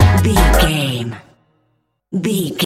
Aeolian/Minor
G#
drum machine
synthesiser
hip hop
Funk
confident
energetic
bouncy
funky